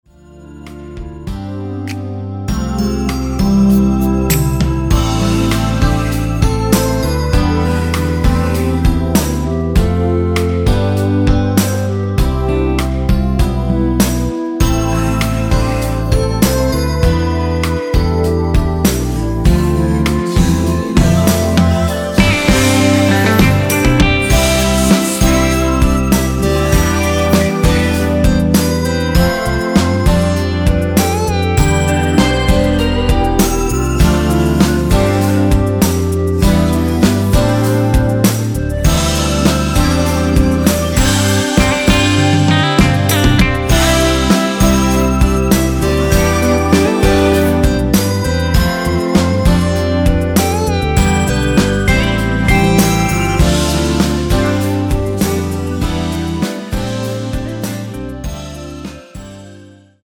원키에서(-2)내린 멜로디와 코러스 포함된 MR입니다.(미리듣기 참조)
앞부분30초, 뒷부분30초씩 편집해서 올려 드리고 있습니다.
중간에 음이 끈어지고 다시 나오는 이유는